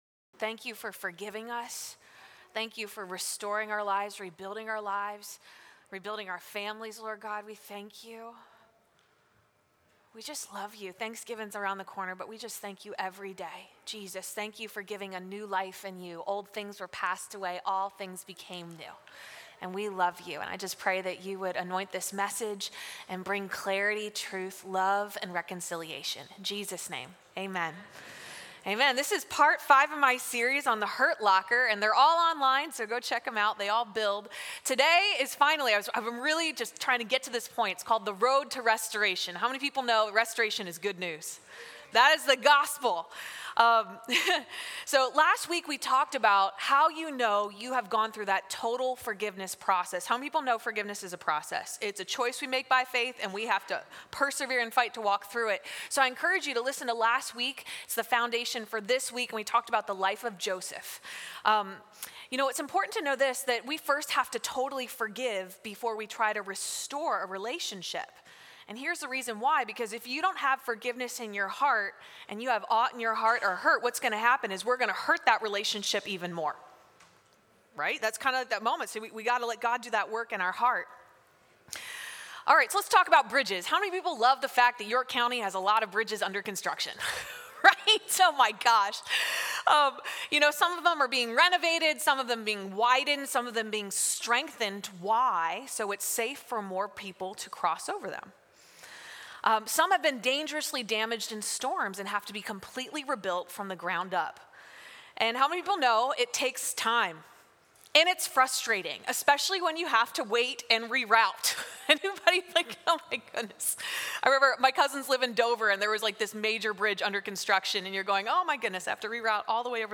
Sunday AM Service